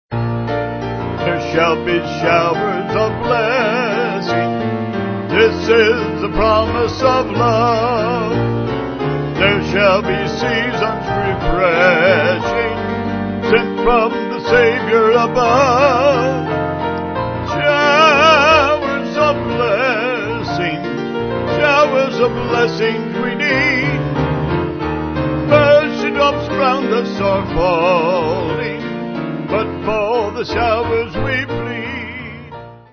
2/Bb